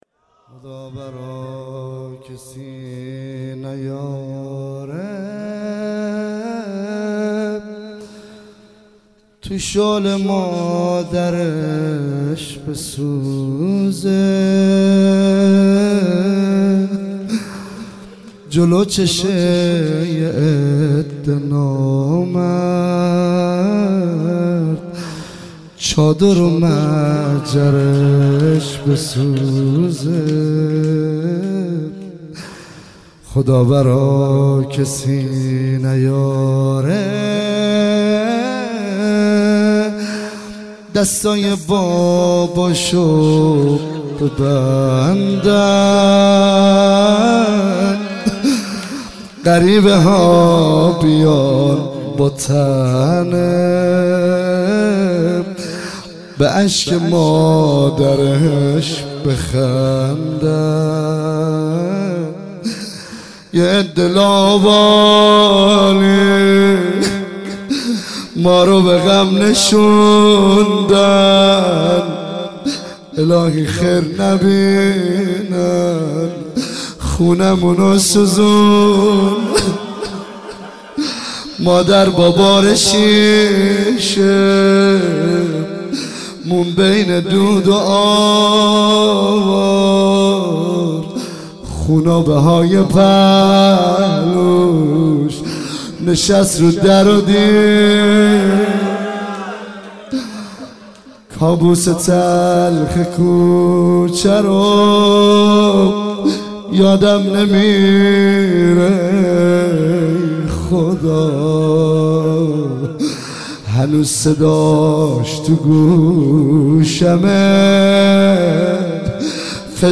فاطمیه 96 - 26 بهمن - گرگان - روضه - خدا برا کسی نیاره